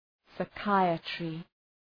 Προφορά
{saı’kaıətrı}